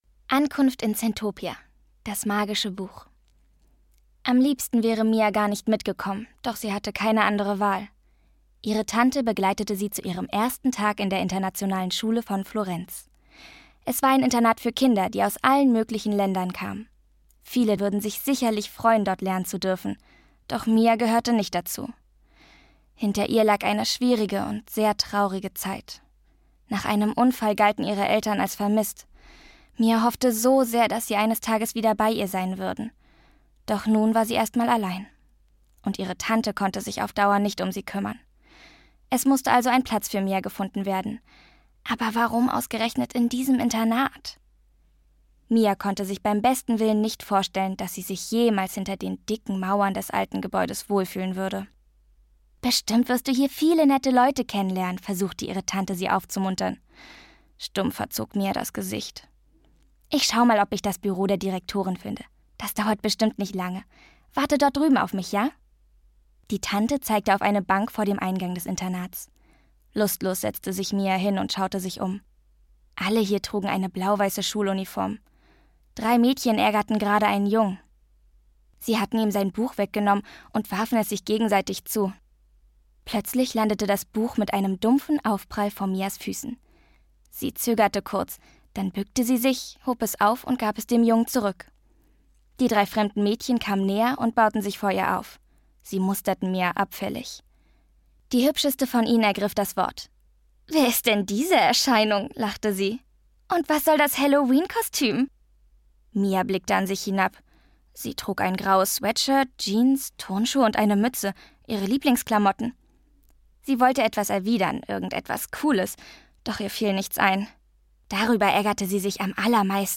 Lesung mit Musik